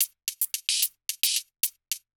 Index of /musicradar/ultimate-hihat-samples/110bpm
UHH_ElectroHatA_110-03.wav